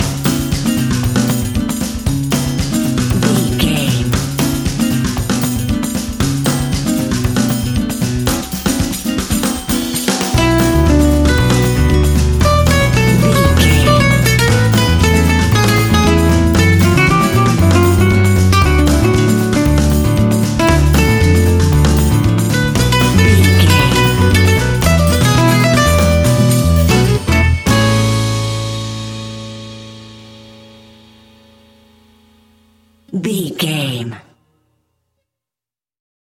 An exotic and colorful piece of Espanic and Latin music.
Ionian/Major
flamenco
maracas
percussion spanish guitar